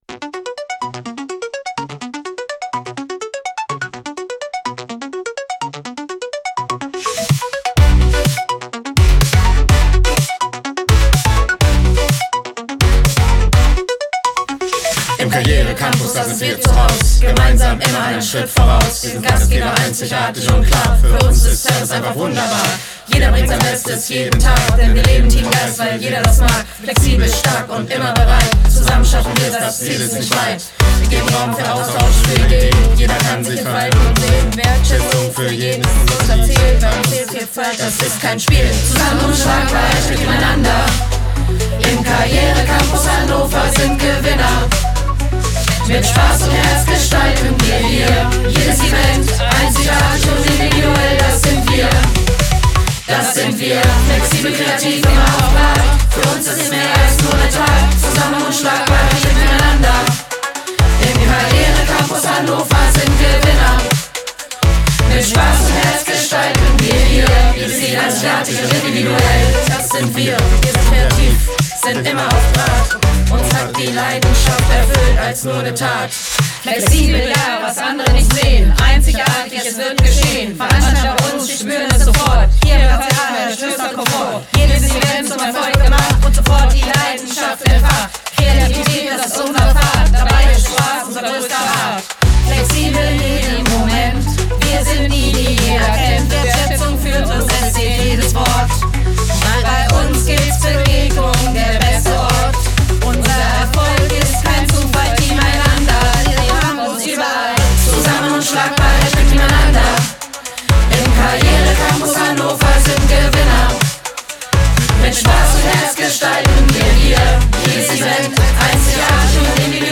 KI-Firmenhymne / KI-Teamevent:
Firmenhymne / Teamevent: